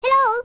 • Prior to the release of Breath of the Wild, Navi is one of the few characters with any voice acting in the longtime series which uses actual English words.
OOT_Navi_Hello3.wav